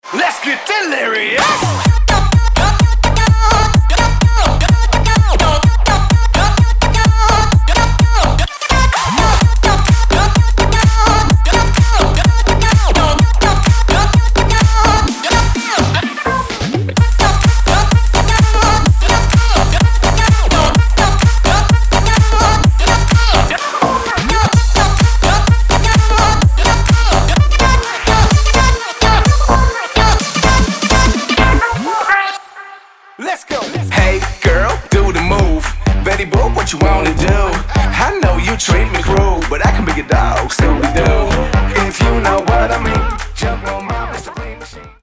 • Качество: 320, Stereo
громкие
club
Bass
Стиль: Electro House